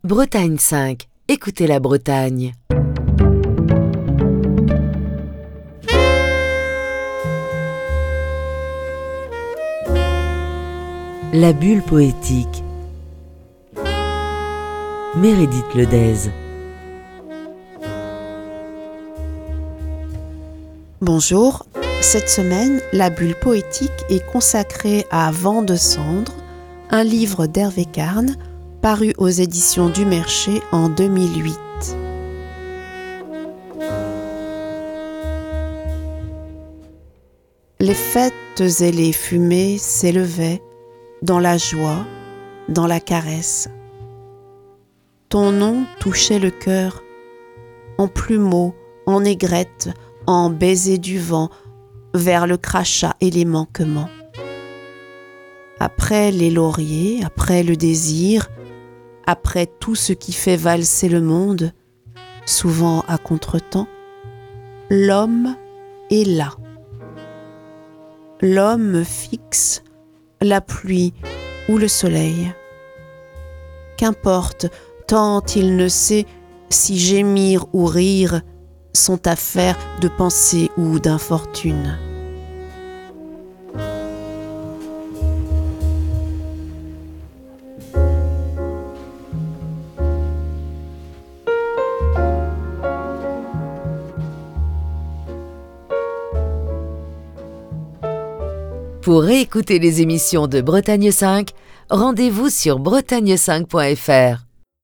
lecture de textes